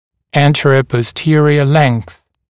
[ˌæntərəpɔs’tɪərɪə leŋ(k)θ][ˌэнтэрэпос’тиэриэ лэн(к)с]длина в переднезаднем направлении